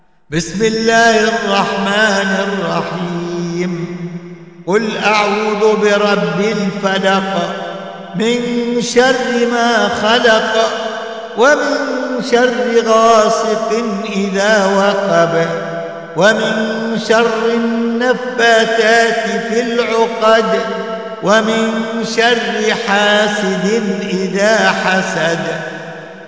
دروس التجويد وتلاوات